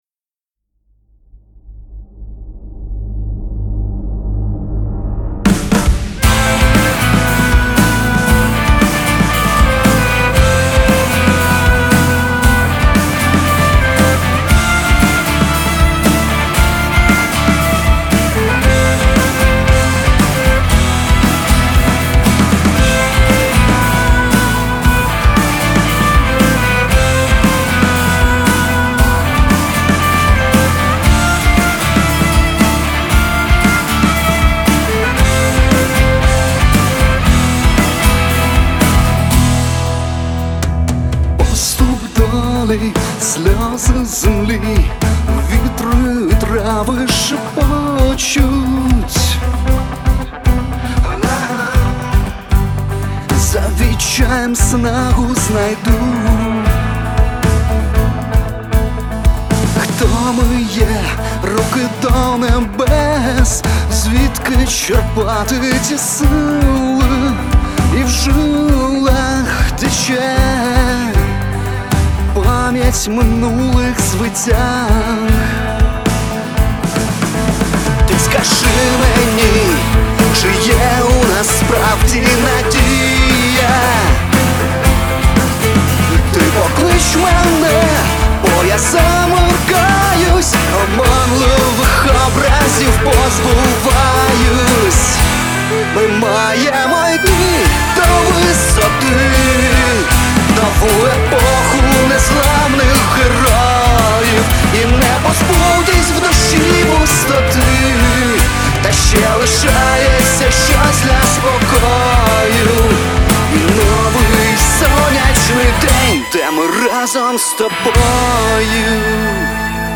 • Жанр: Pop, Rock